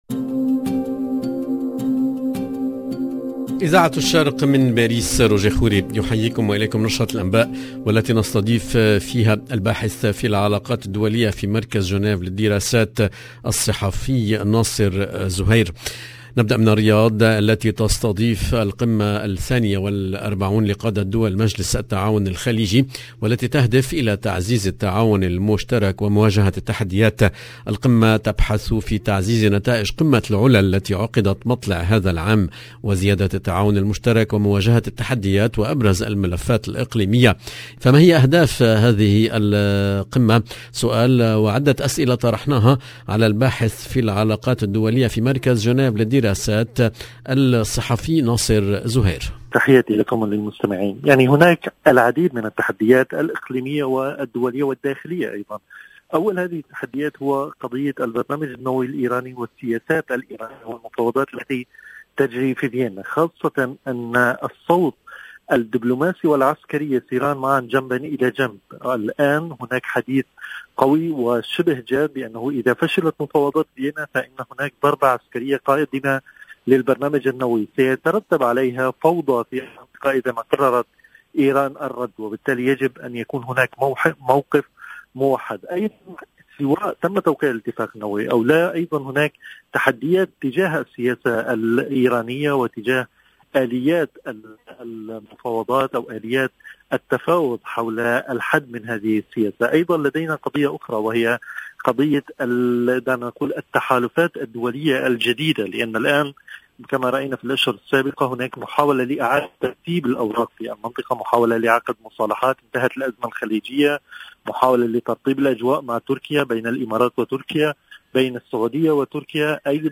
LE JOURNAL DU SOIR EN LANGUE ARABE DU 14/12/21